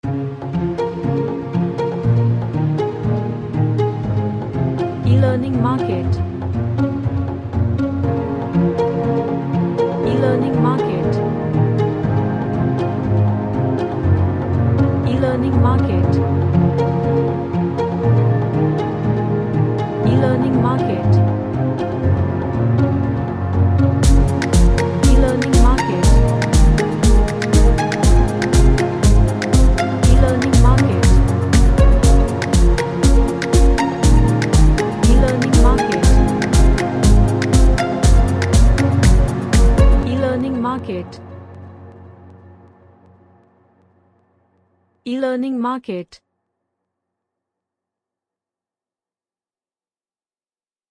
A Track featuring arps and Strings.
Happy